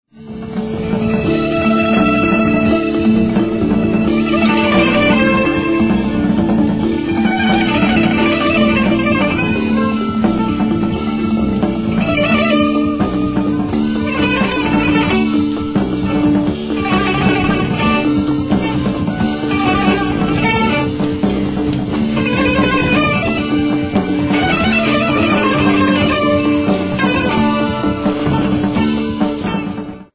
Genre: Jazz/Rock
Drums
Guitar, Vocals
Sax, Electric Violin
Bass